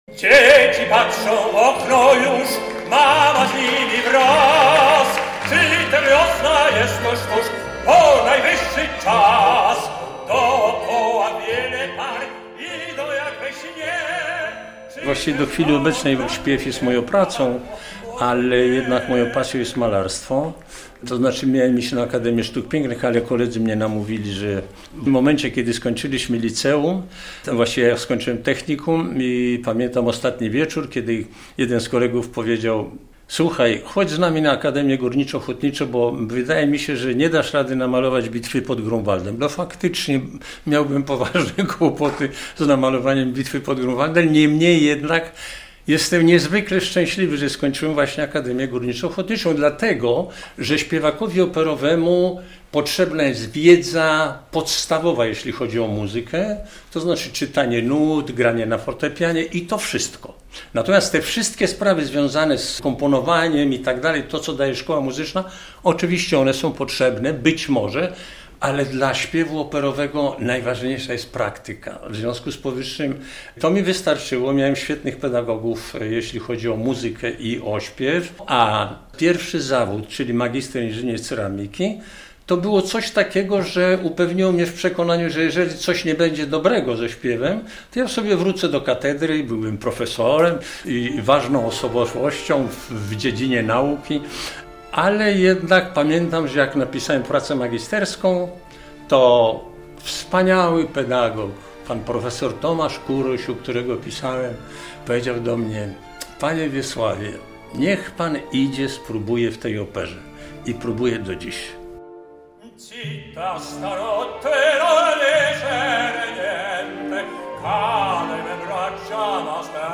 Legenda polskiej i światowej sceny operowej, znakomity tenor, Wiesław Ochman był specjalnym gościem koncertu karnawałowego w Operze i Filharmonii Podlaskiej.
Koncert zakończył się wspólnym odśpiewaniem przez solistów i publiczność szlagieru "Usta milczą dusza śpiewa" z operetki Wesoła Wdówka.